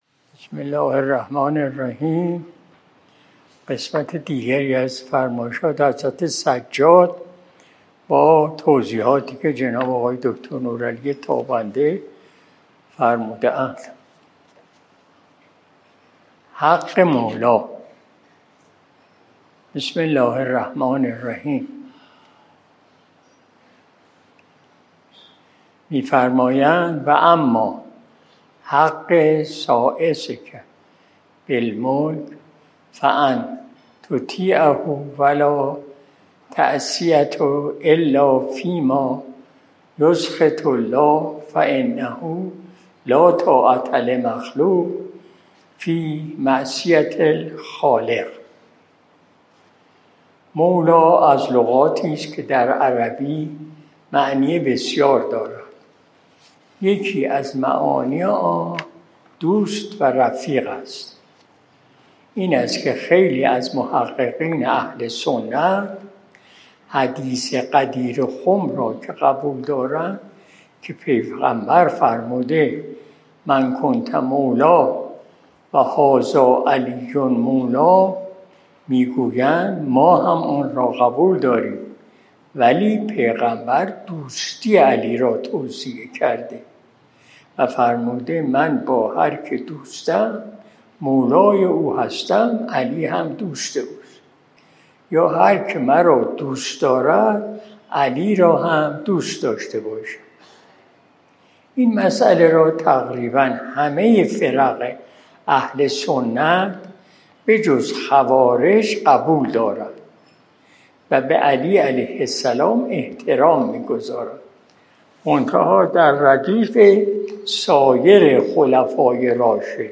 مجلس شب جمعه ۱۶ شهریور ماه ۱۴۰۲ شمسی